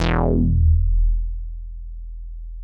MICROMOOG E2.wav